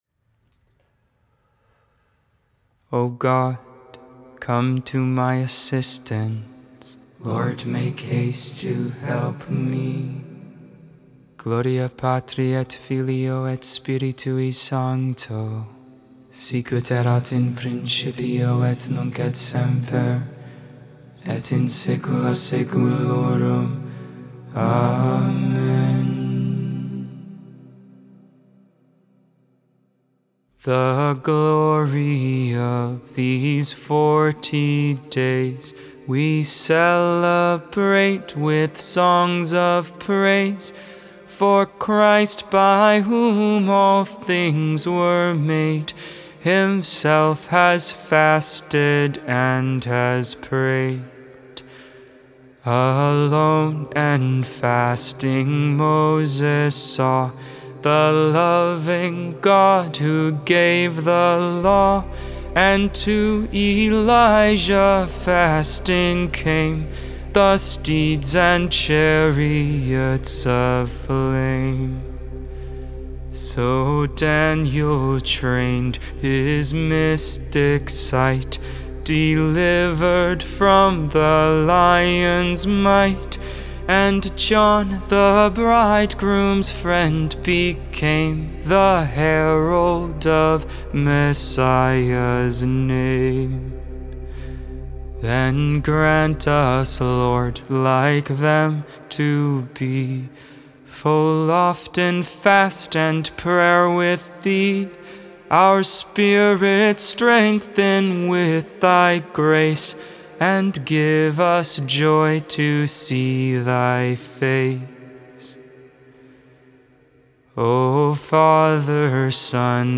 Hymn: The Glory of These Forty Days